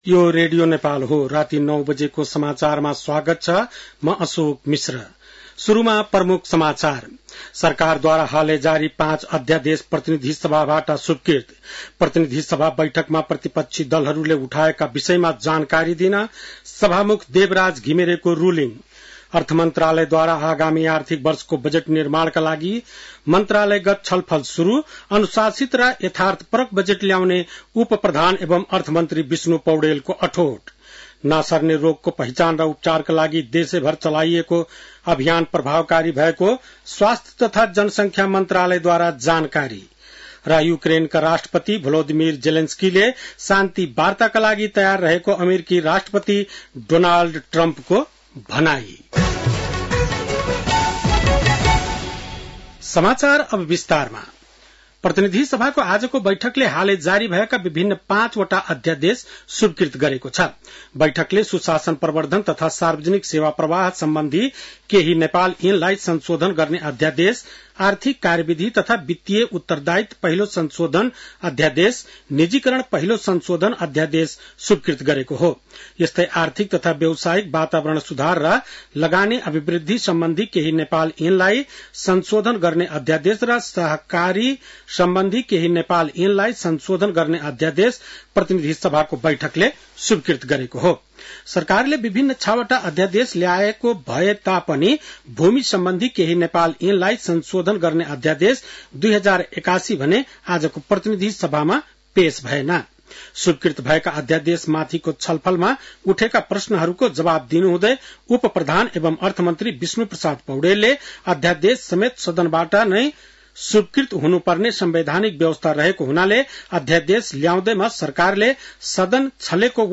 बेलुकी ९ बजेको नेपाली समाचार : २२ फागुन , २०८१